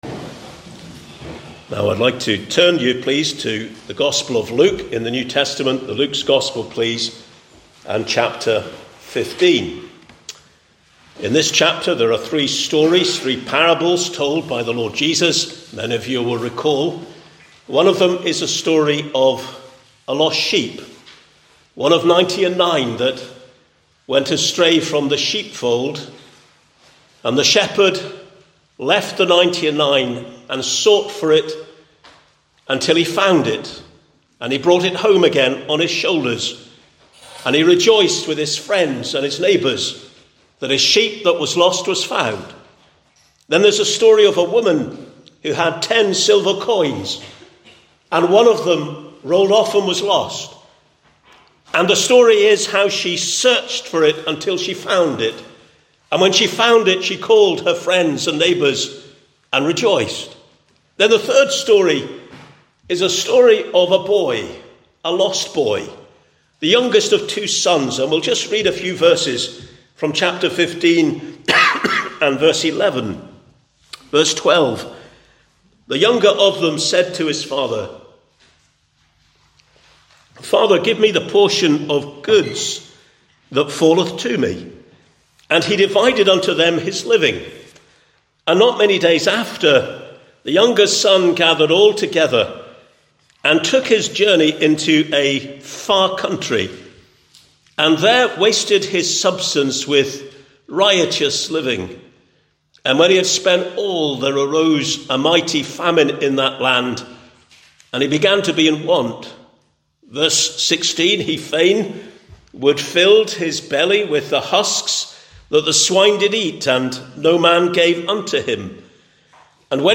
Various Gospel Messages